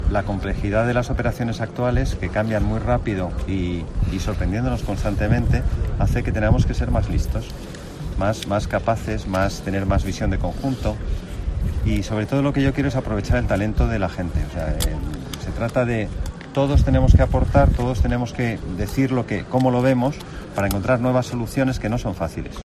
Declaraciones del nuevo general de la Brilat, Alfonso Pardo de Santayana, tras su toma de mando en Figueirido